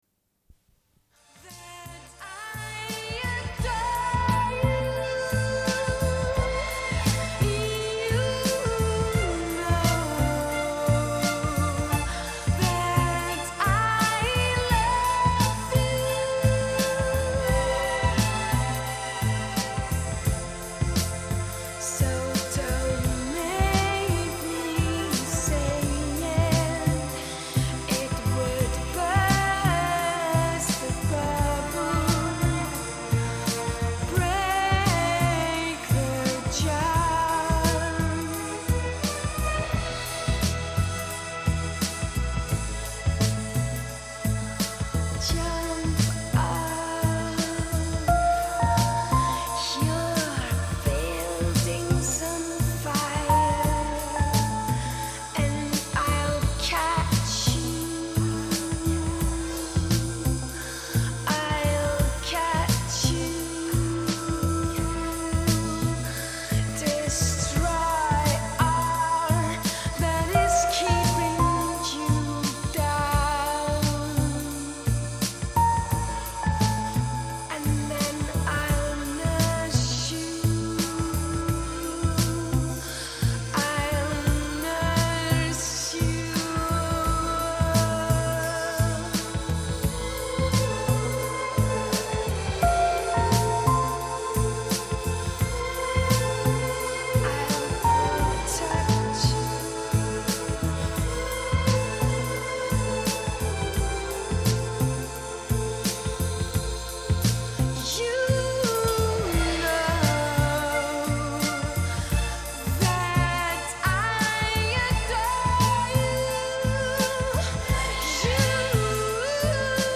Une cassette audio, face A31:22